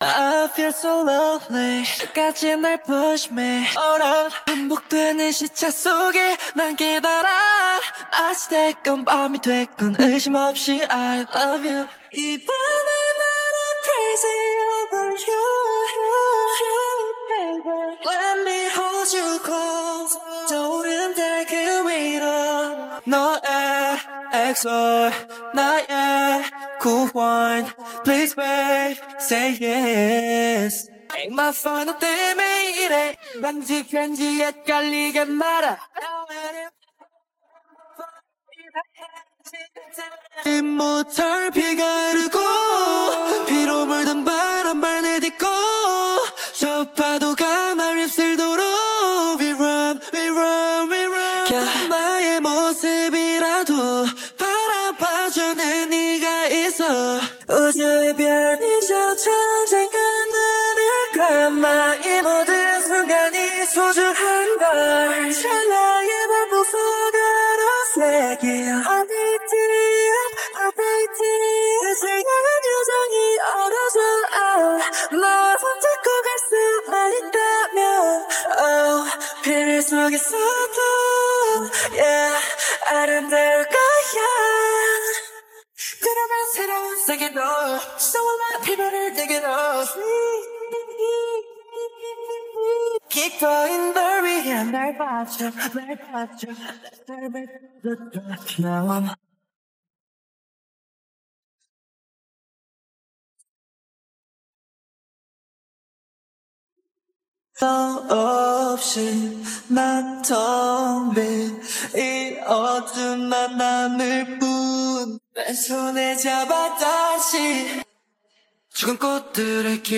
KPOP
KIMSUNOOMODEL / sample 1-D-77bpm-440hz (Vocals) (Mel-RoFormer Karaoke).mp3